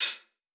TC2 Live Hihat10.wav